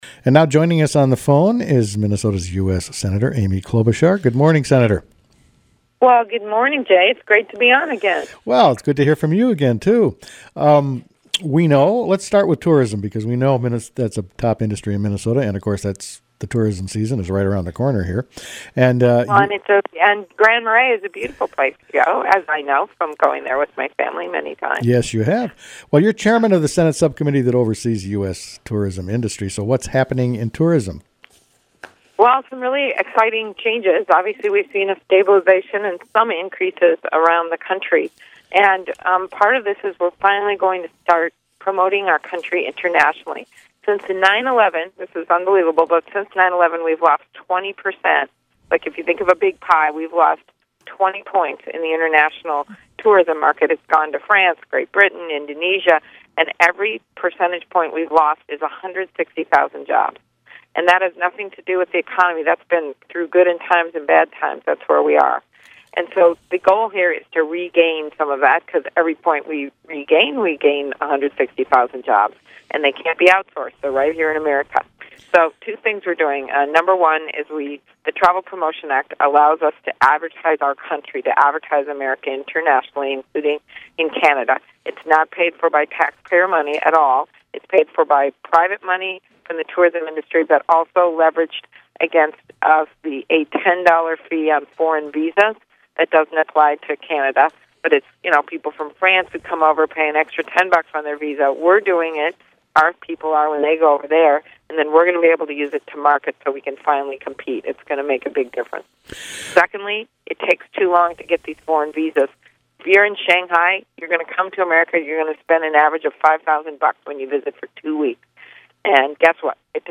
Amy KlobucharInterview.mp3